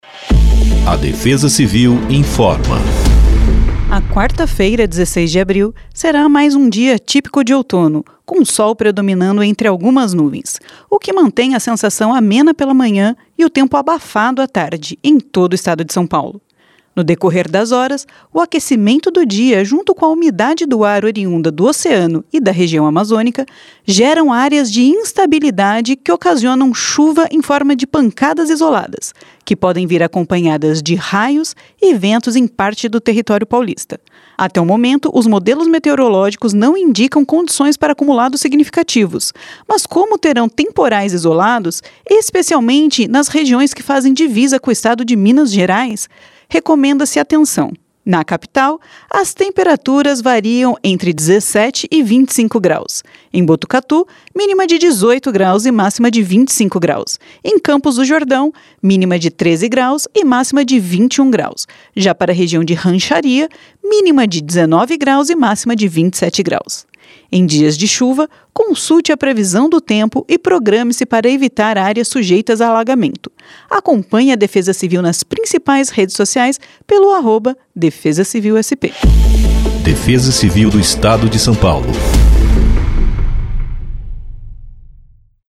Defesa-Civil-Boletim-Previsao-do-Tempo-para-1604-Spot.mp3